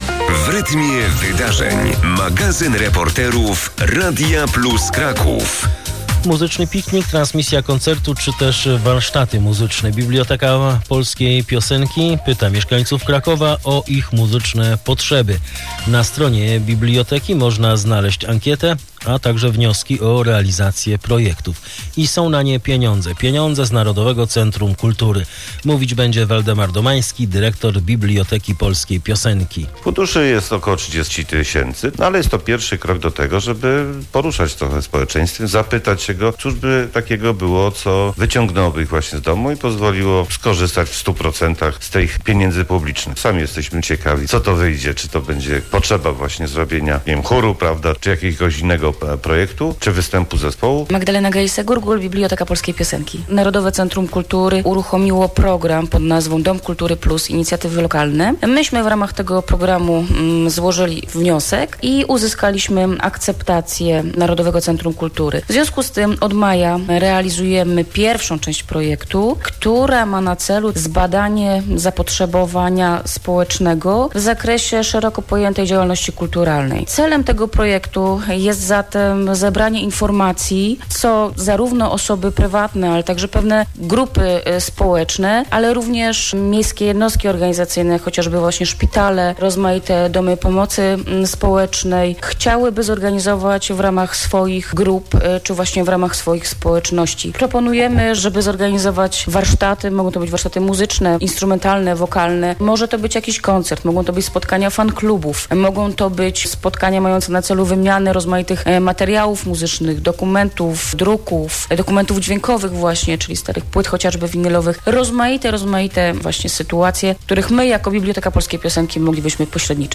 AUDYCJE RADIOWE